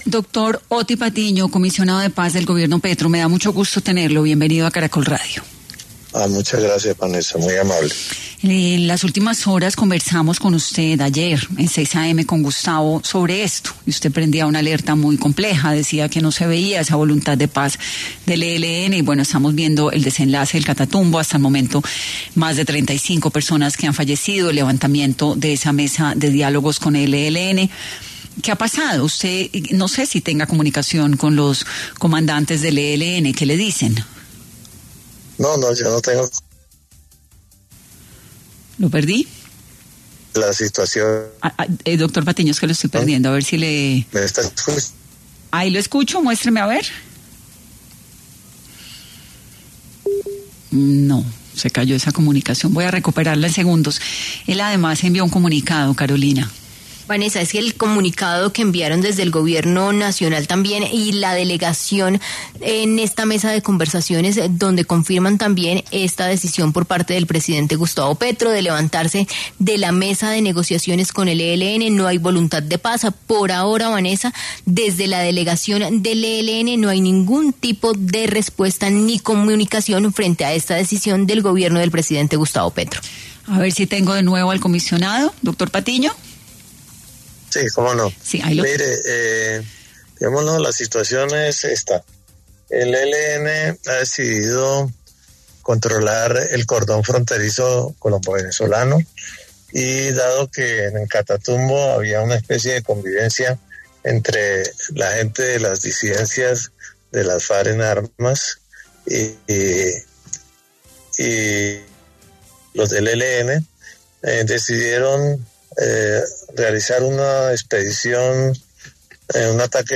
Otty Patiño, comisionado para la Paz, habló en 10AM sobre lo que está sucediendo en el Catatumbo, ¿Se pudo haber evitado los enfrentamientos?